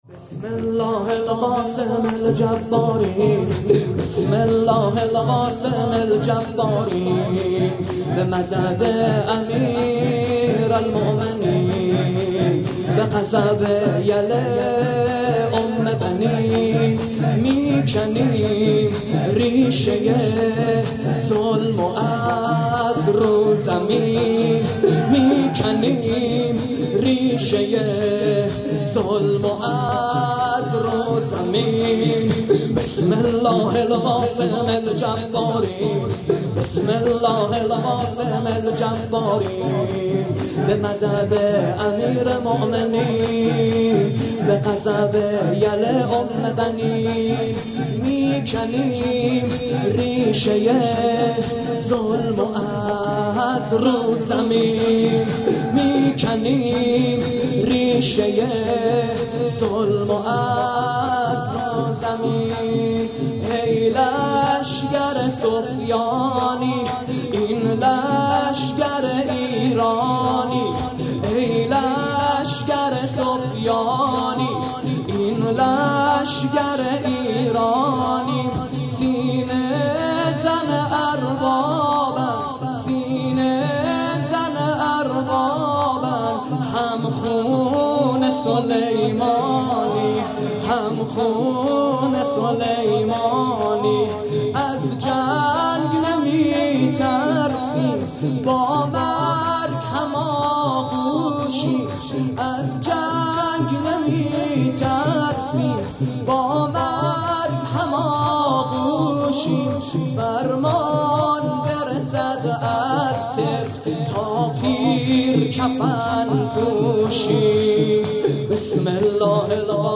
◼عزاداری دهه اول محرم - ۱۳۹۹/۶/۷
دهه اول محرم 99 مطیع امر گزارش_صوتی